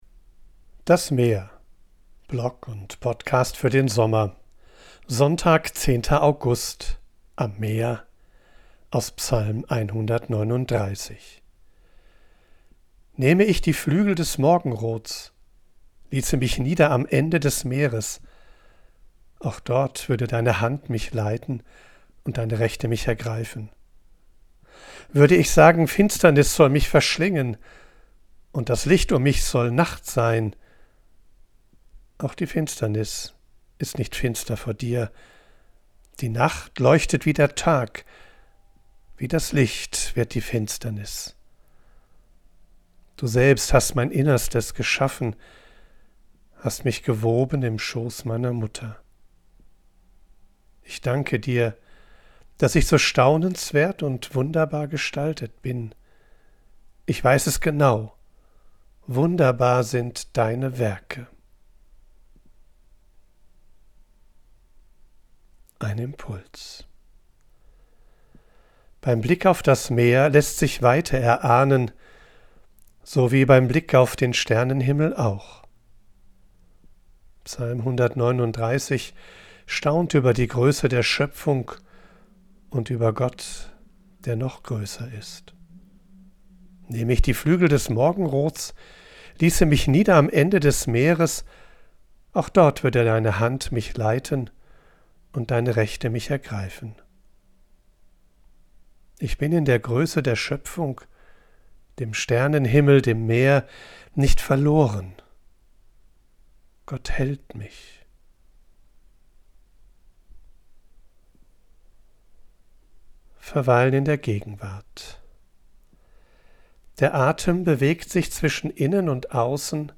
Ich bin am Meer und sammle Eindrücke und Ideen.
von unterwegs aufnehme, ist die Audioqualität begrenzt. Dafür
mischt sie mitunter eine echte Möwe und Meeresrauschen in die